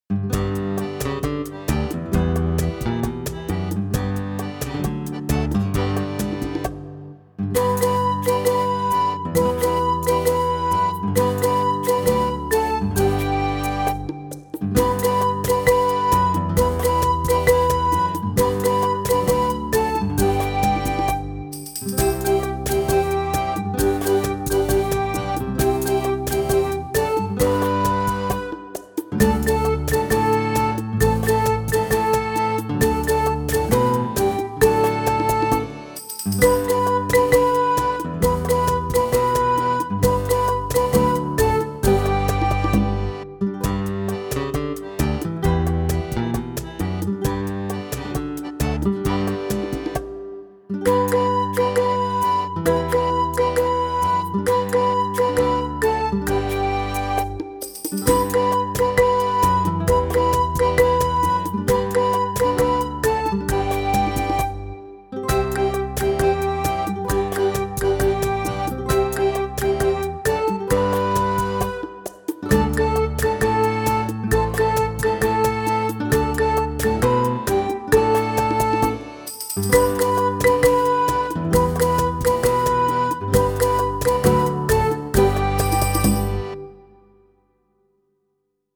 The whole tune is repeated in Verse 2.